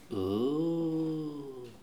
Les sons ont été découpés en morceaux exploitables. 2017-04-10 17:58:57 +02:00 314 KiB Raw Permalink History